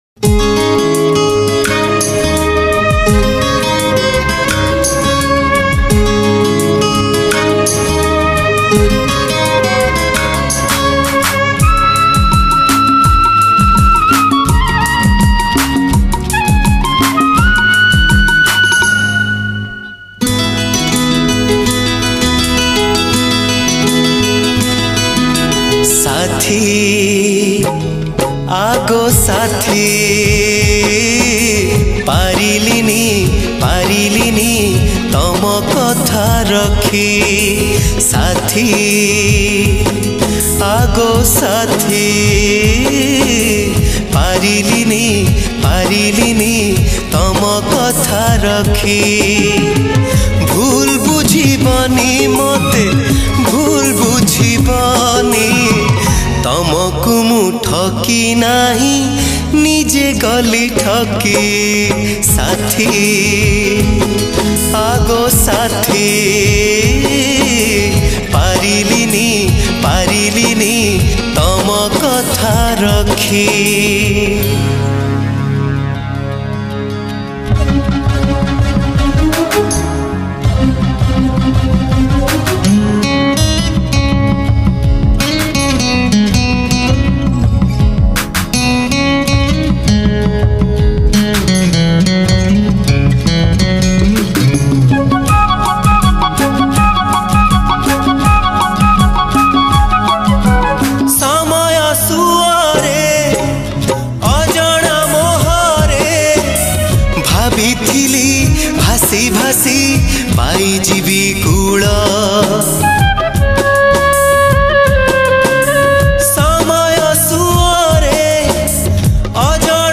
Cover Version Odia Sad Song